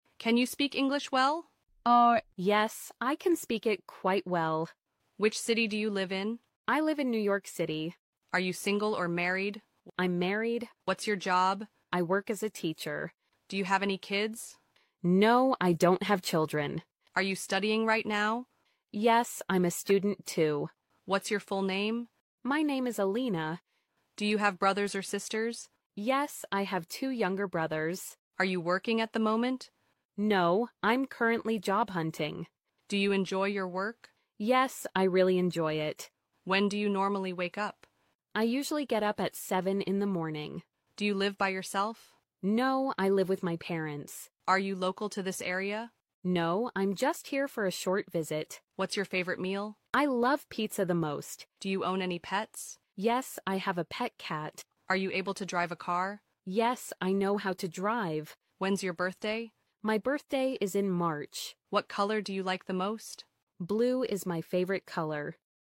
daily speaking practice through dialogue.